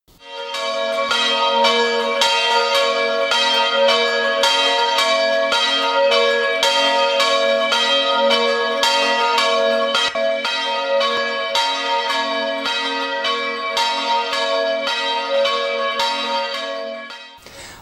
In Oudehorne en Katlijk, bij Heerenveen, wordt het Thomasluiden (van 21 tot 31 december) nog steeds in ere gehouden.
Jongeren hebben de kunst van het luiden van hun vaders overgenomen en zich ook de zogenaamde vierkante slag eigen gemaakt. Daarbij gaat het erom, met de grote klok te beginnen en vervolgens de slag van de kleine klok daar precies tussenin te ‘plaatsen’.